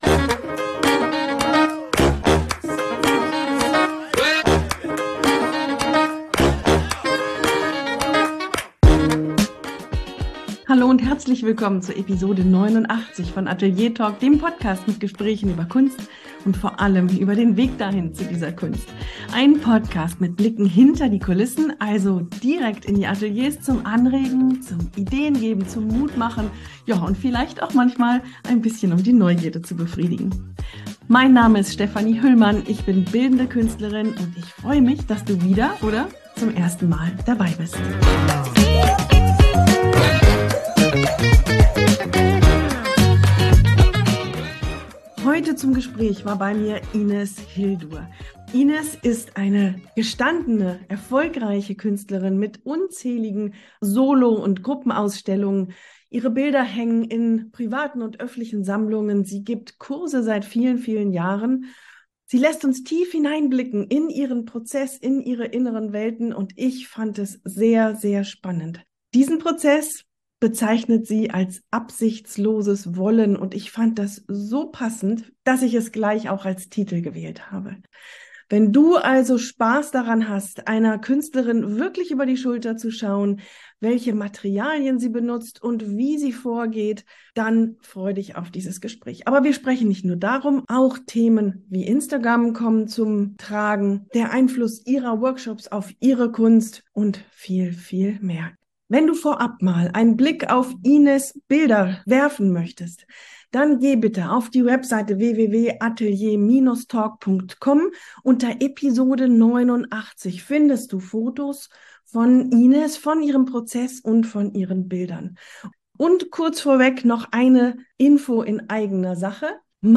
In meinem Gespräch mit der Malerin